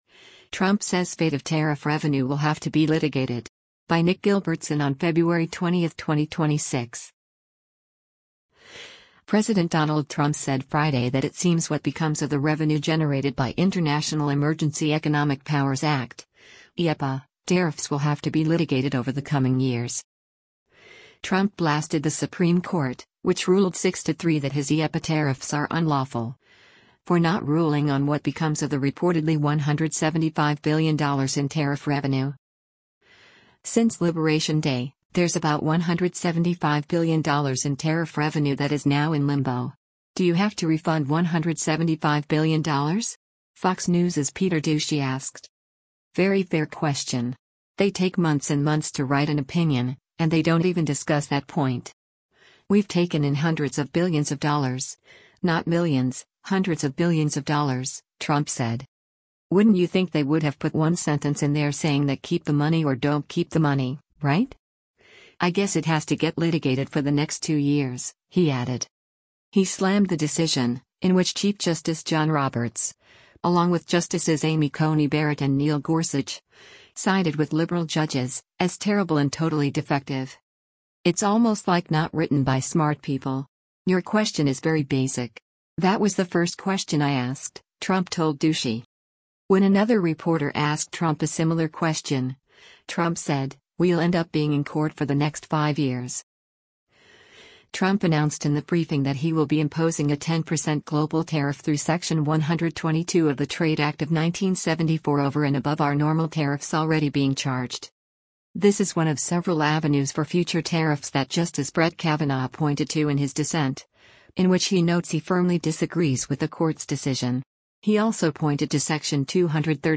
“Since Liberation Day, there’s about $175 billion in tariff revenue that is now in limbo. Do you have to refund $175 billion?” Fox News’s Peter Doocy asked.
Trump announced in the briefing that he will be imposing a 10 percent global tariff through Section 122 of the Trade Act of 1974 “over and above our normal tariffs already being charged.”